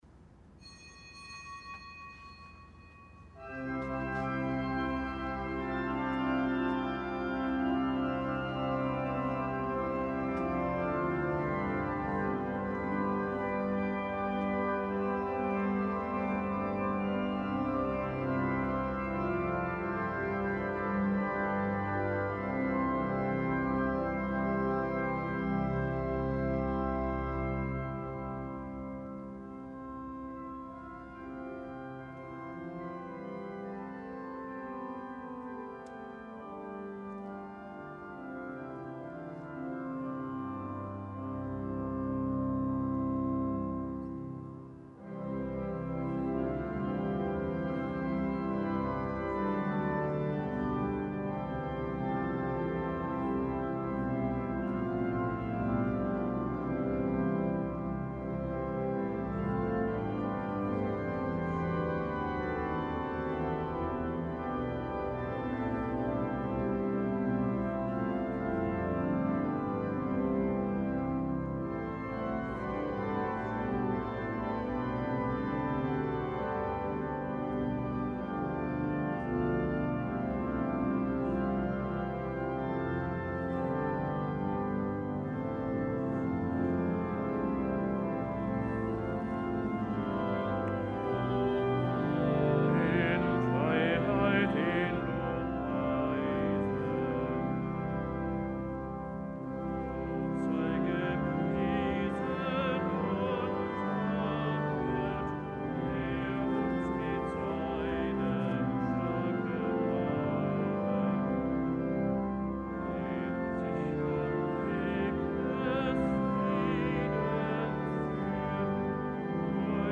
Gottesdienst
Heilige Messe aus dem Kölner Dom am Mittwoch der 22. Woche im Jahreskreis.